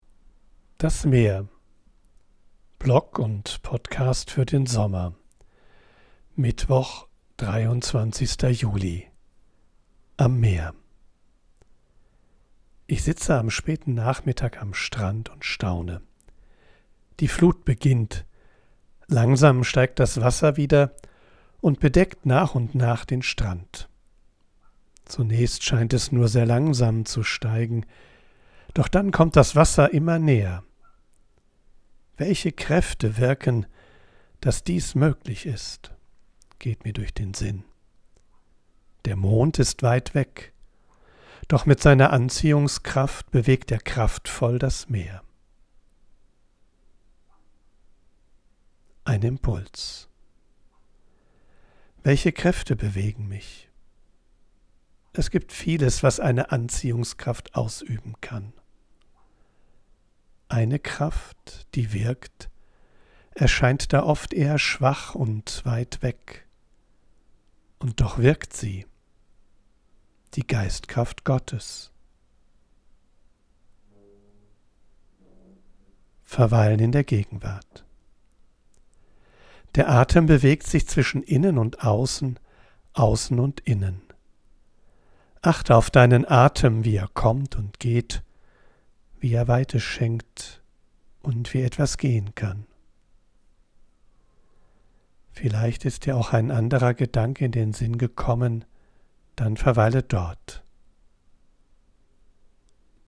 live. Ich bin am Meer und sammle Eindrücke und Ideen. Weil ich
von unterwegs aufnehme, ist die Audioqualität begrenzt. Dafür
mischt sie mitunter eine echte Möwe und Meeresrauschen in die